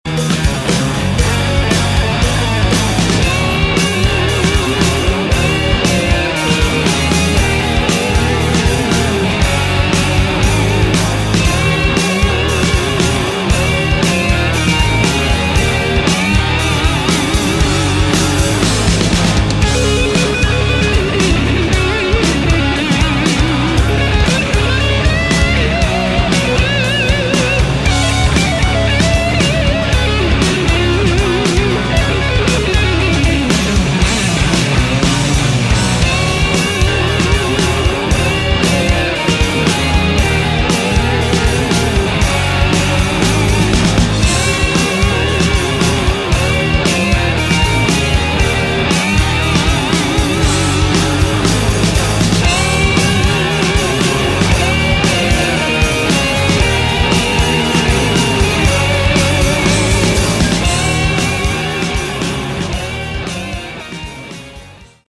Category: Hard Rock
Drums, Percussion